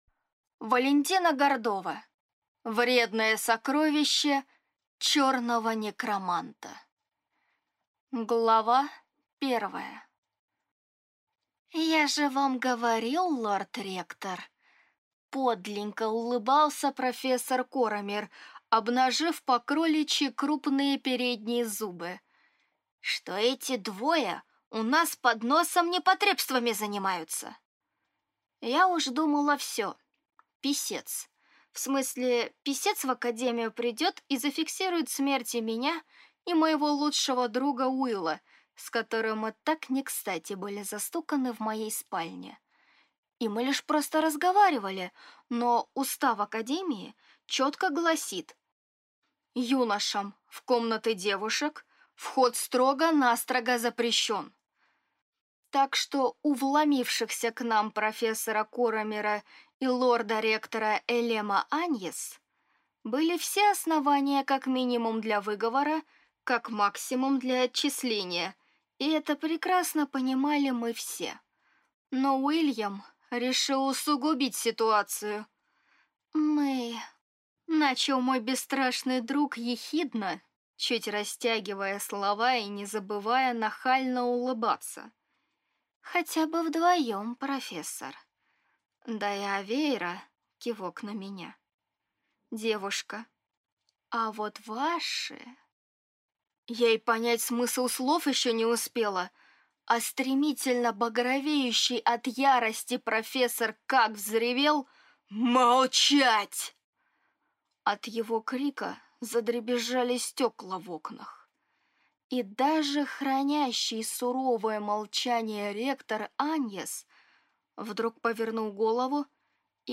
Аудиокнига Вредное сокровище Чёрного некроманта | Библиотека аудиокниг